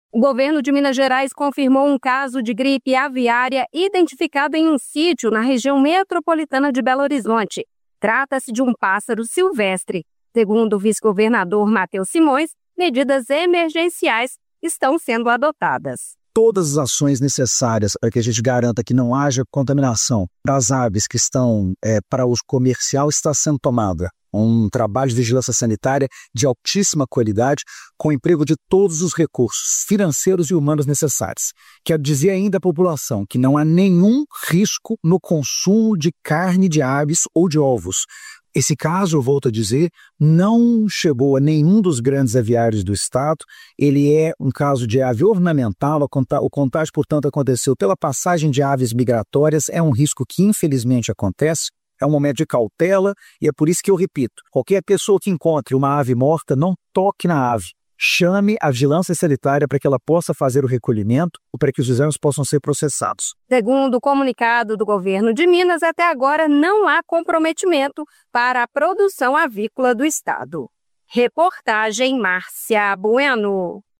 Como explicou o ministro do Desenvolvimento e Assistência Social, Família e Combate à Fome, Wellington Dias.